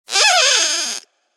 squeak.ogg.mp3